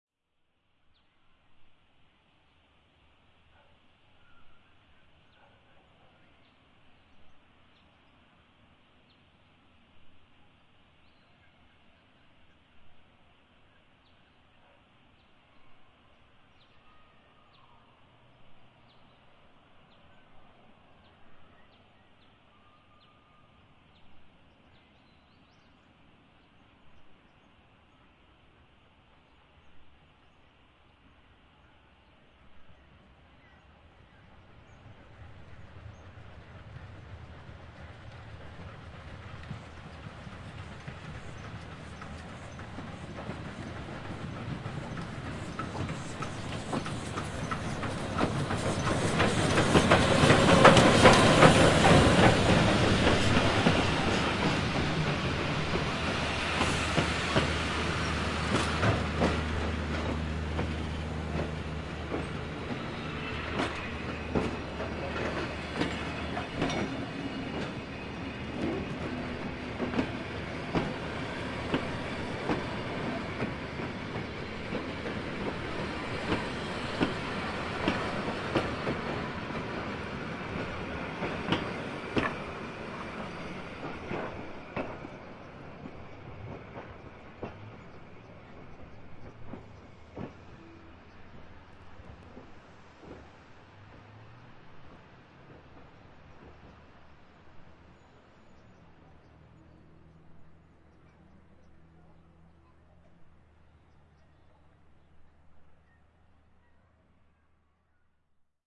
火车通过的声音
描述：在火车站附近的天桥上录制火车通过时的声音。
标签： 声音 铁路 火车 铁轨 拟音
声道立体声